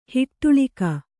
♪ hiṭṭuḷika